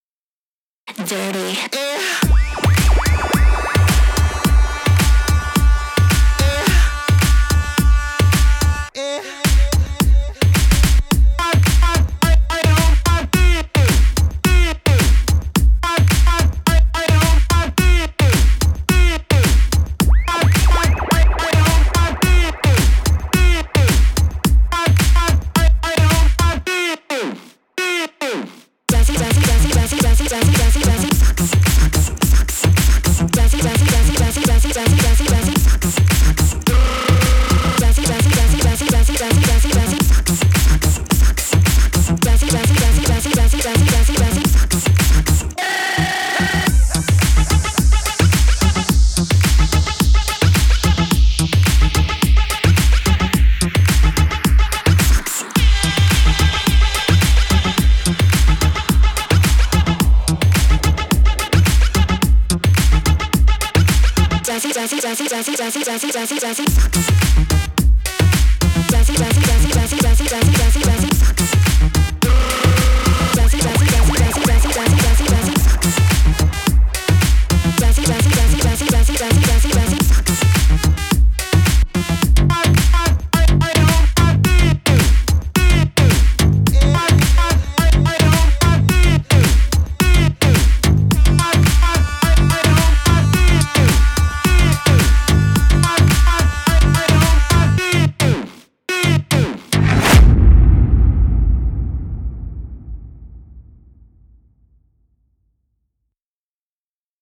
super tight with some goofy spots.